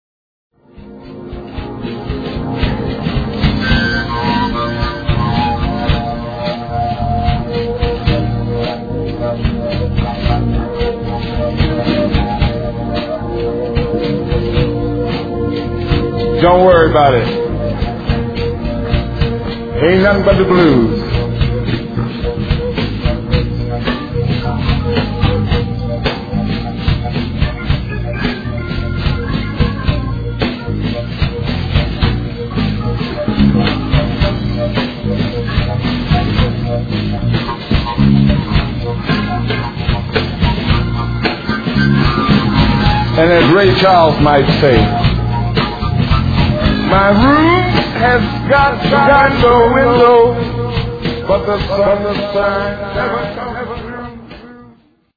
BLUES
Live from Prague [2000].